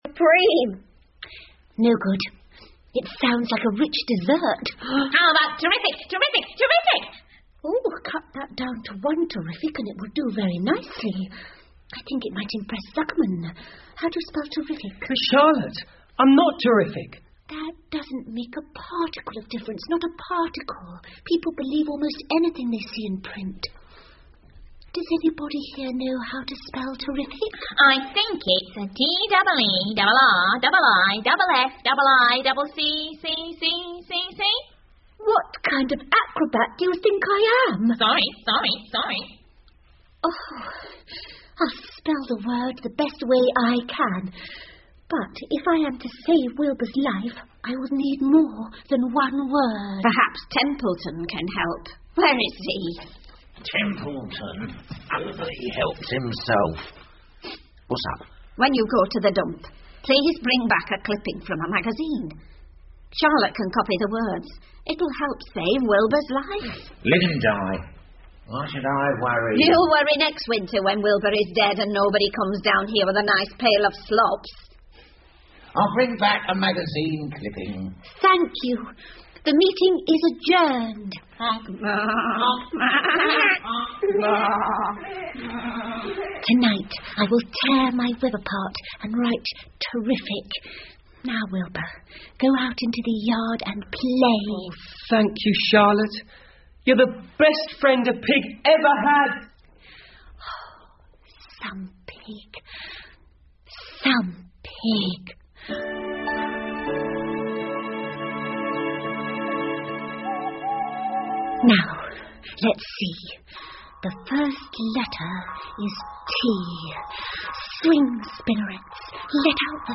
夏洛的网 Charlottes Web 儿童广播剧 7 听力文件下载—在线英语听力室